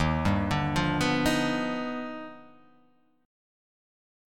D#+M9 chord